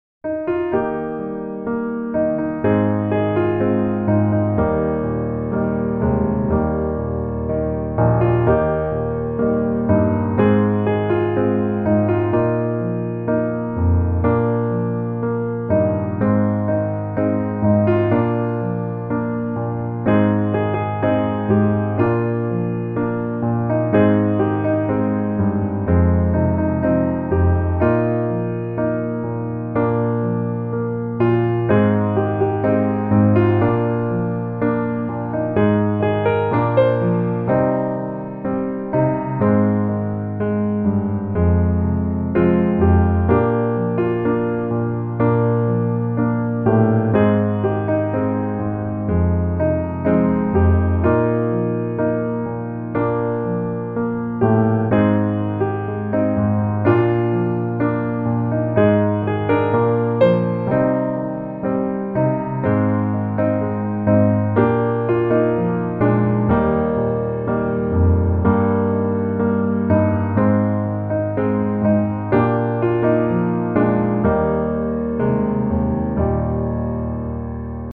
Eb Major